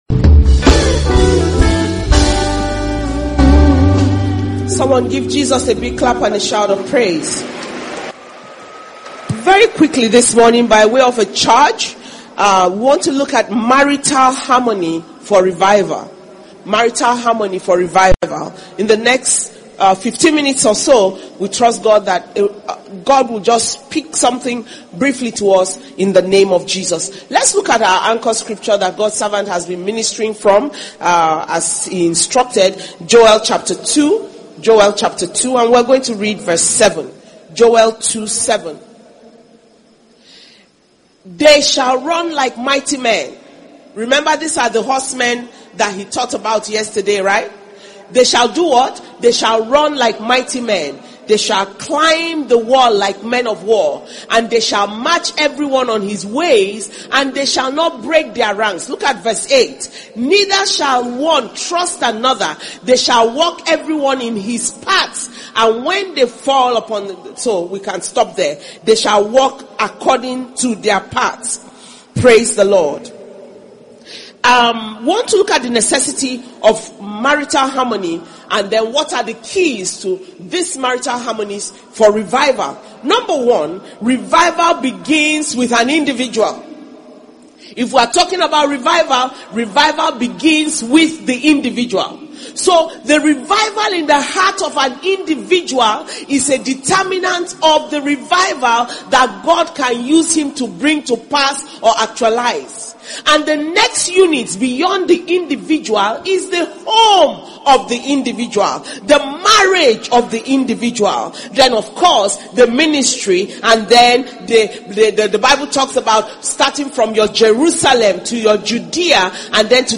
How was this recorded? International Flaming Fire Conference 2023 - Day 4 Morning Session